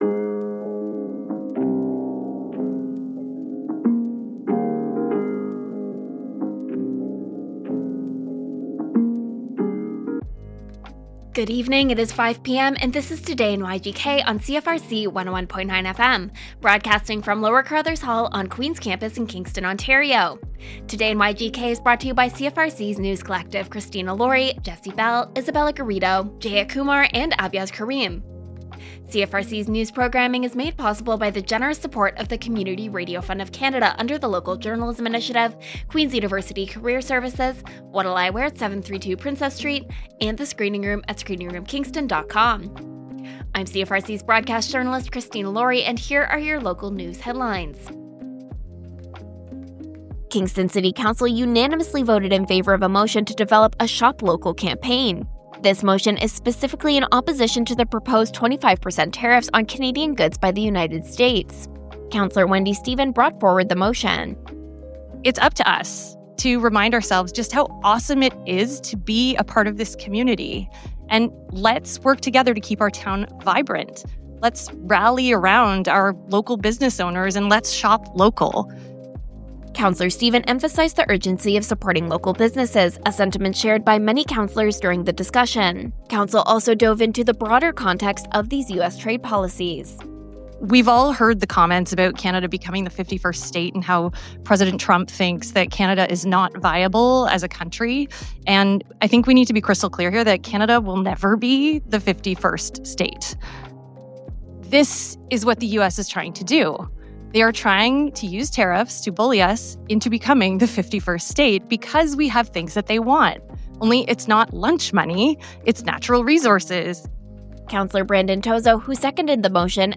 Local news with CFRC’s News Team.